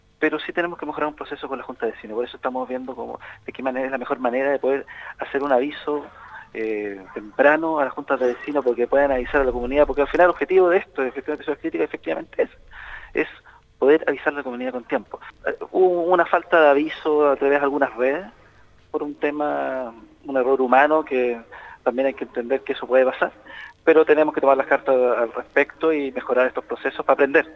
En conversación con el Programa Haciendo Ciudad de Radio Sago, el seremi indicó que el problema en cuestión radicó en un “error humano”.